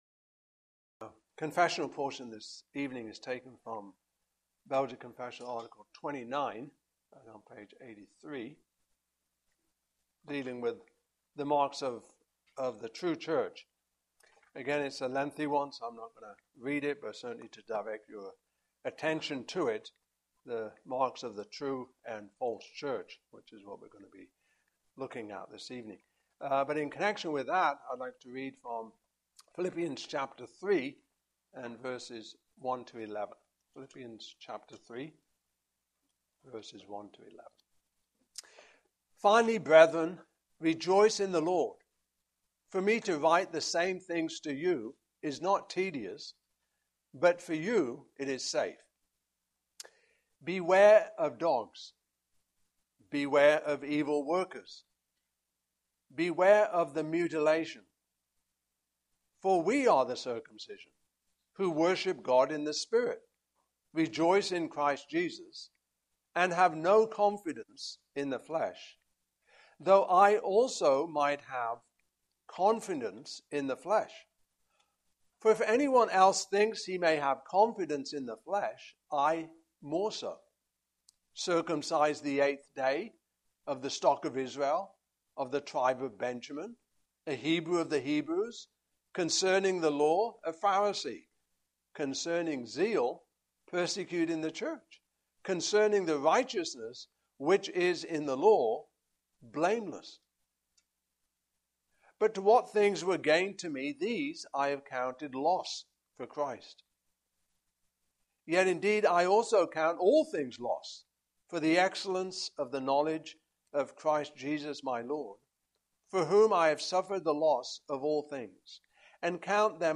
Belgic Confession 2023 Passage: Philippians 3:1-11 Service Type: Evening Service Topics